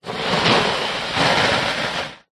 Фонтан воды, вырывающийся из дыхала кита 2